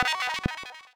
1up_a.wav